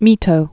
(mētō)